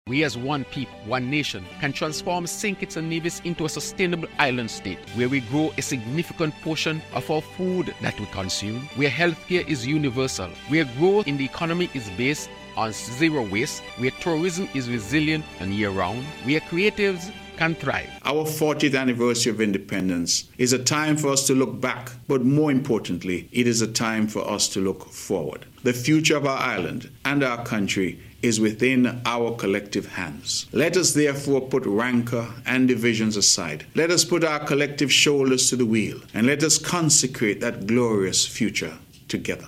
On the occasion of the nation’s 40th Independence Celebration, the Prime Minister and Premier as well as political figures in the federation addressed citizens and residents on September 19th.
They spoke on the progress of St. Kitts and Nevis so far as an Independent state and the journey ahead in becoming a sustainable island state. Here are Prime Minister, Dr. Terrance Drew and Premier of Nevis, Hon. Mark Brantley: